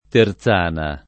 [ ter Z# na ]